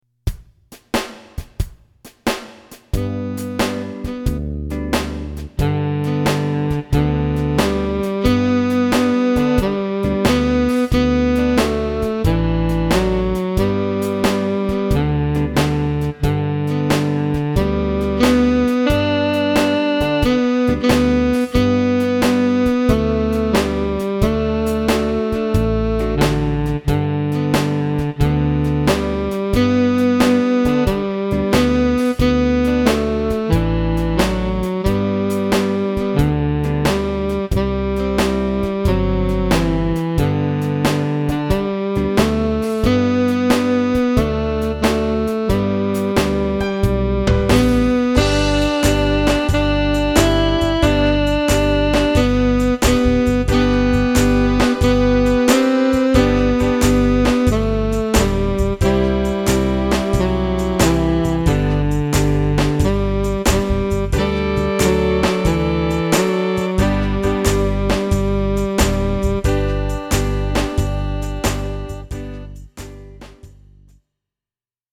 Instrumental (Descargar)